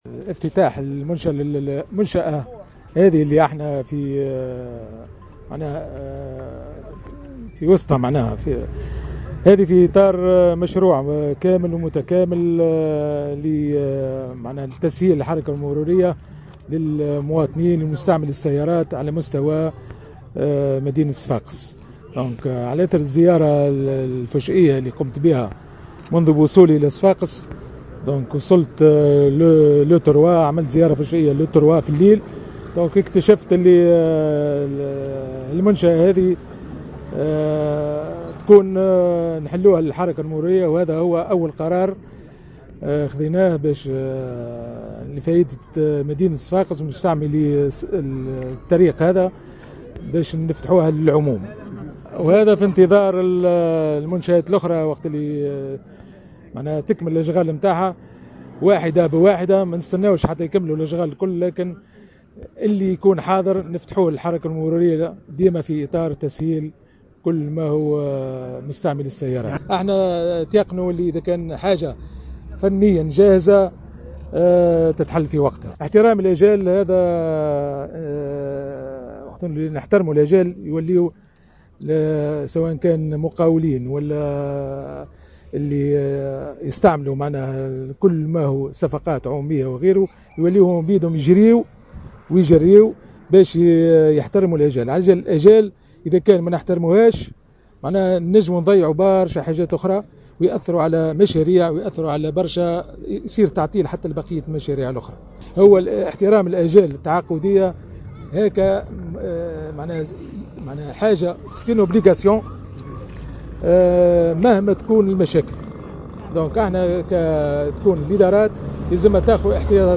وقال الوالي في تصريح لمراسلة الجوهرة إن هذه الممرات ستساهم بشكل كبير في تخفيف الضغط على طرقات وسط المدينة و تسهل حركة المرور التي أصبحت إشكالا بصفاقس وسيتم التسريع في انجاز القسط الاول وبقية القسط الثاني من مشروع الممرات العلوية بالطريق الحزامية .